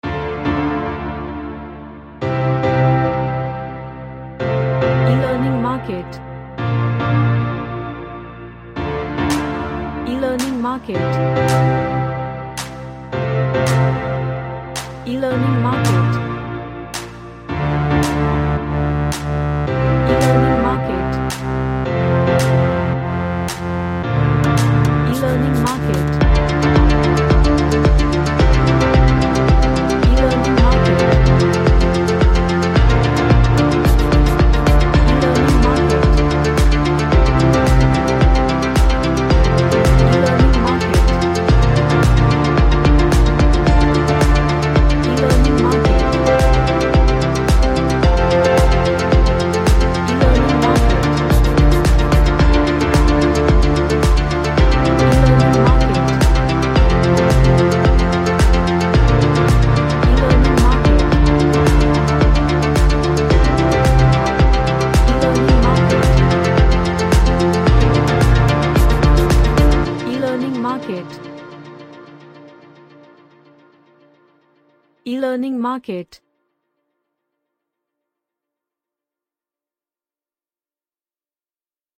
An Electronic Groovy Track.
Happy